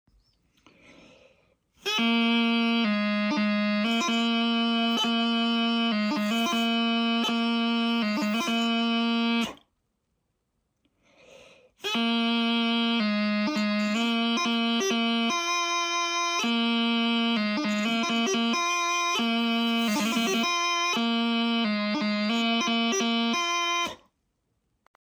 Play it slowly on the practice chanter like this, then try to build up speed: